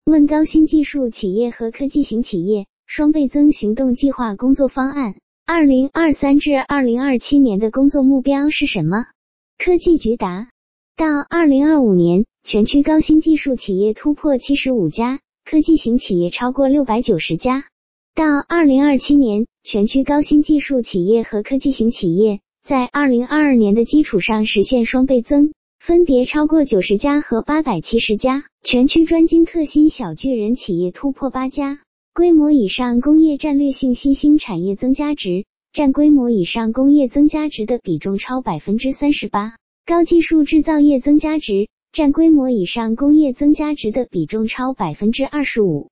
语音播报
语音合成中，请耐心等待...